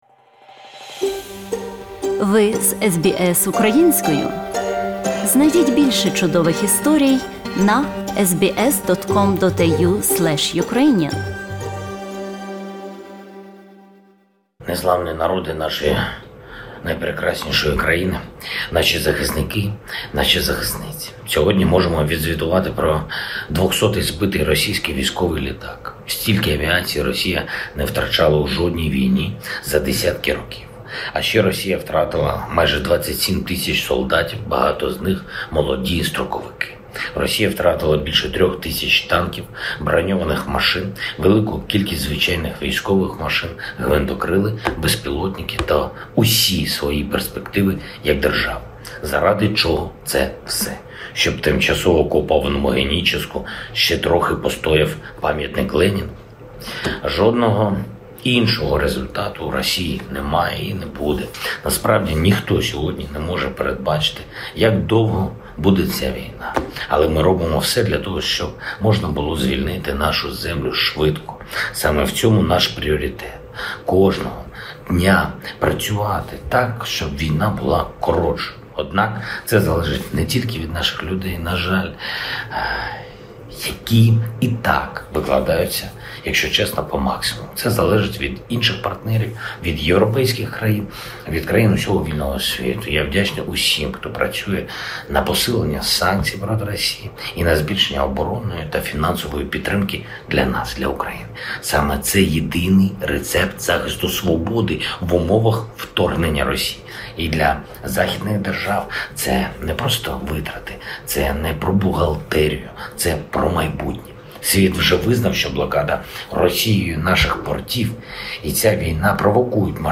Ukrainian President V. Zelenskyy addresses Ukrainian nation